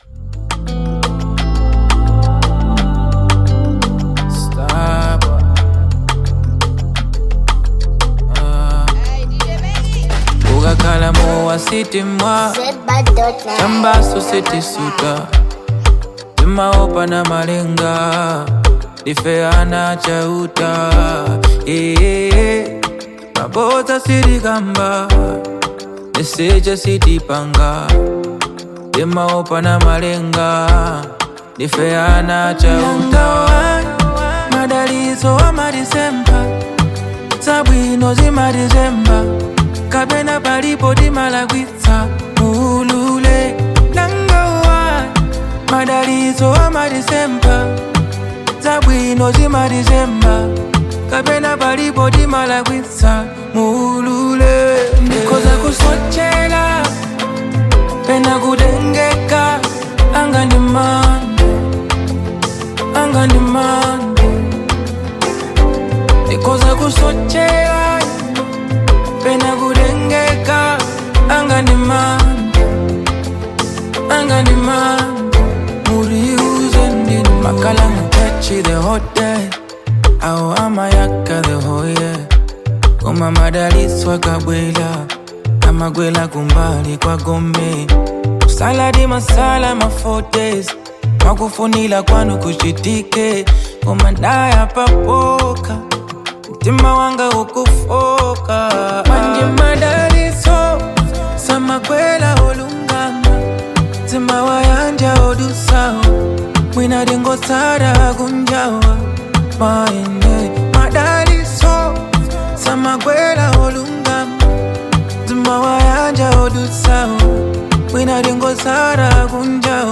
It’s the perfect mix of calm and groove.